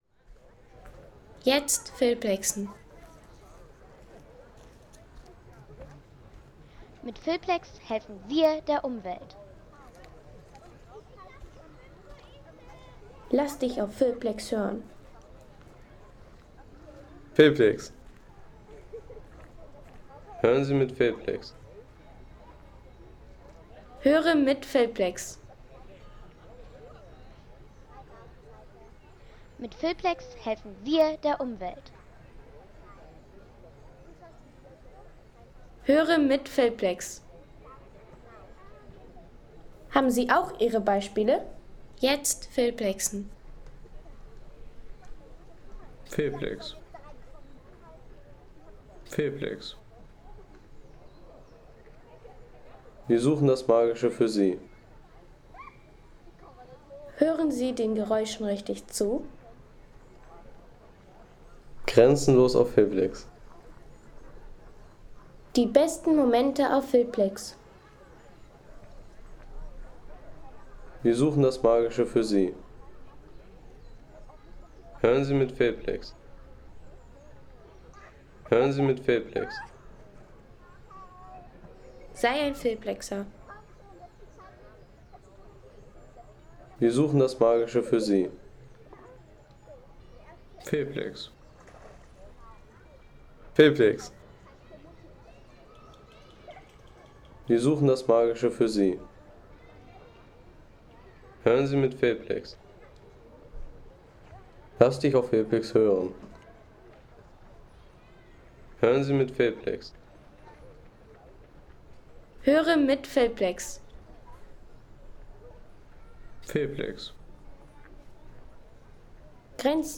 Möserer See Home Sounds Landschaft Bäche/Seen Möserer See Seien Sie der Erste, der dieses Produkt bewertet Artikelnummer: 225 Kategorien: Landschaft - Bäche/Seen Möserer See Lade Sound.... Idyllische Naturgeräusche am Möserer See – Ein alpines Paradies in ... 3,50 € Inkl. 19% MwSt.